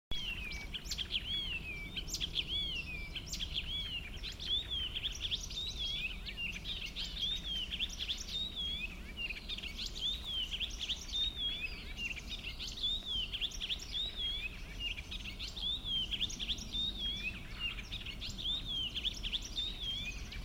Птицы -> Славковые ->
пересмешка, Hippolais icterina
СтатусПоёт